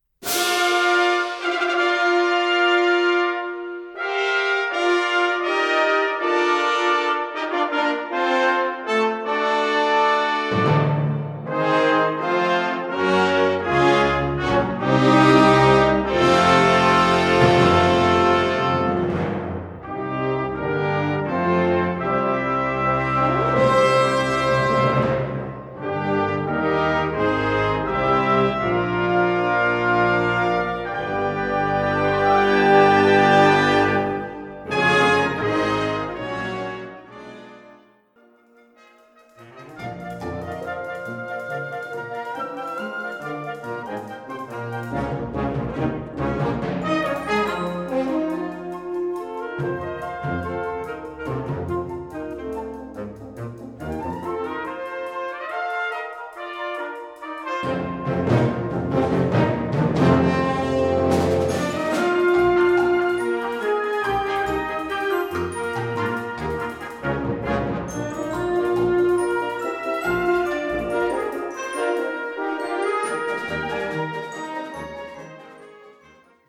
Catégorie Harmonie/Fanfare/Brass-band
Sous-catégorie Musique pour le début du concert
Instrumentation Ha (orchestre d'harmonie)